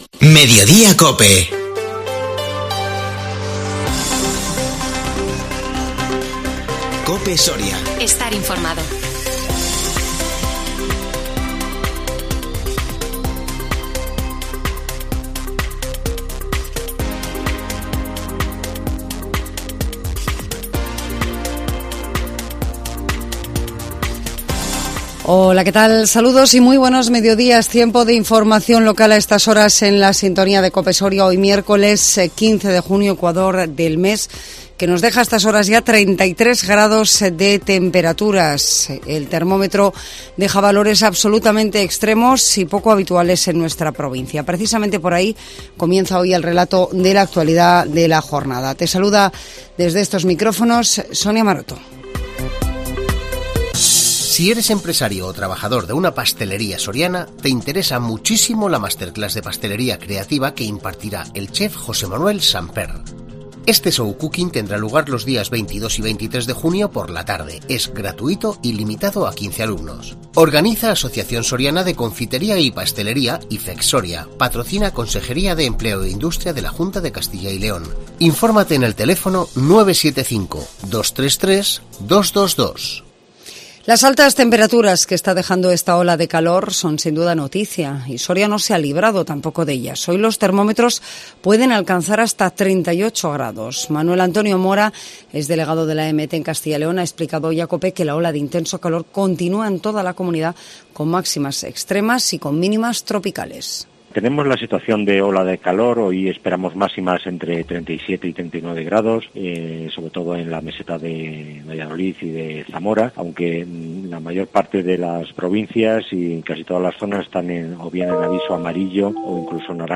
INFORMATIVO MEDIODÍA COPE SORIA 15 JUNIO 2022